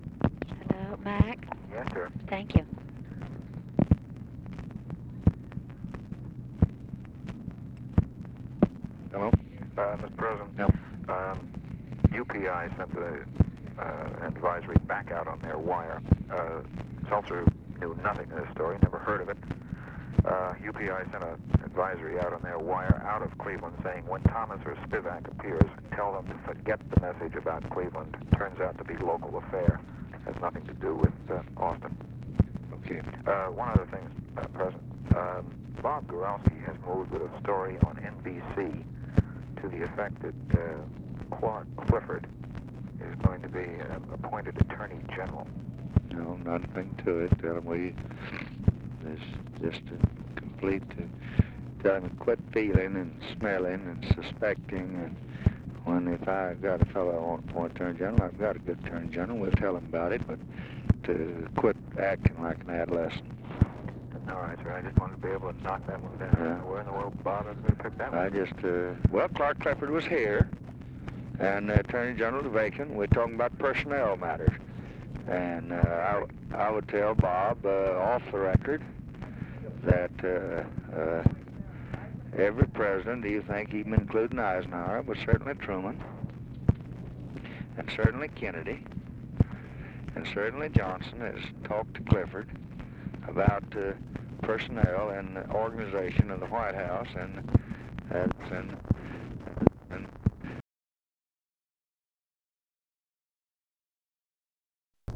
Conversation with MAC KILDUFF, November 12, 1964
Secret White House Tapes